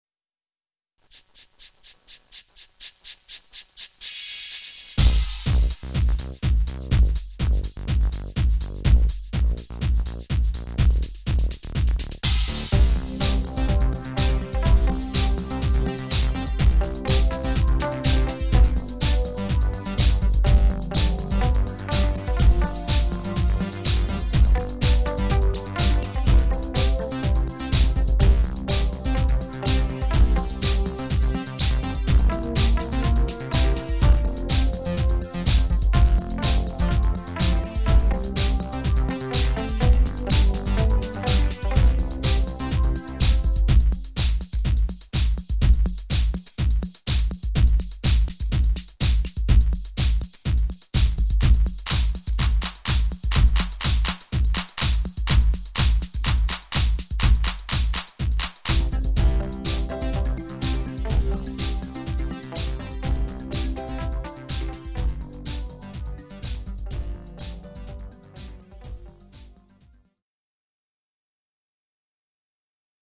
HD = Hoedown/Patter